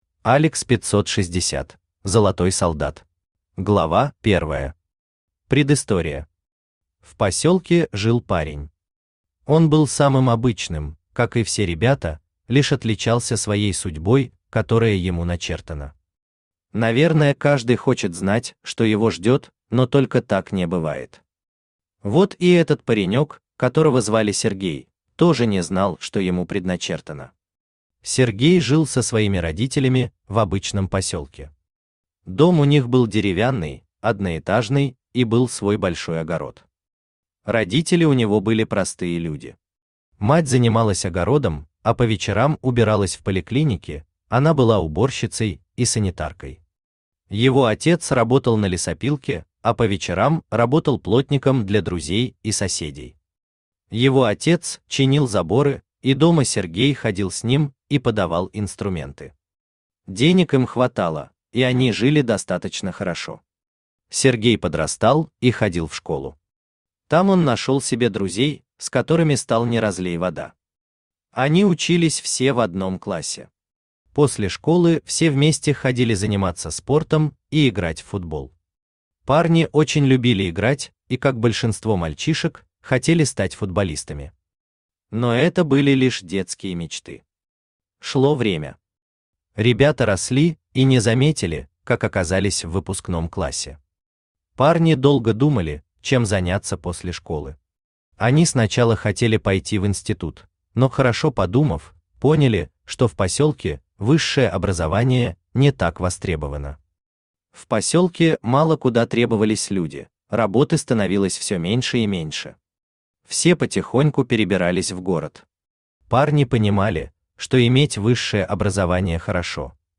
Аудиокнига Золотой солдат | Библиотека аудиокниг
Aудиокнига Золотой солдат Автор ALEX 560 Читает аудиокнигу Авточтец ЛитРес.